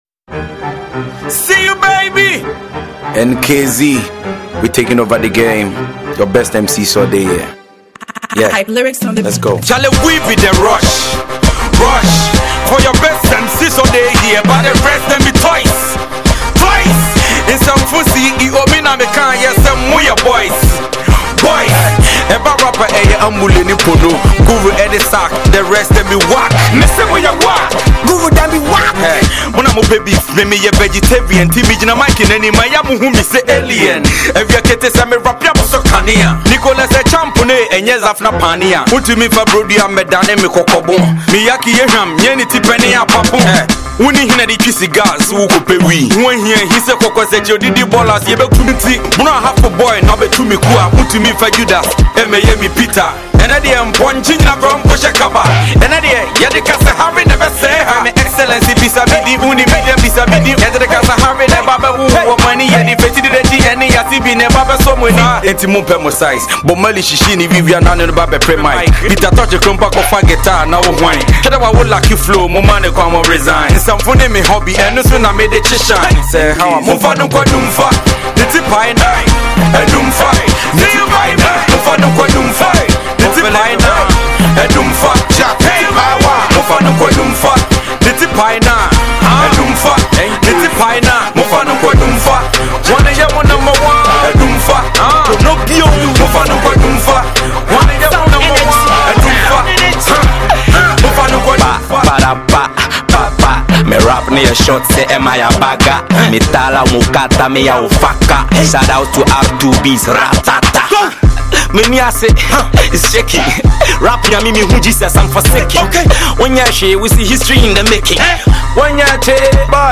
Hip-Hop
Crunk Instrumental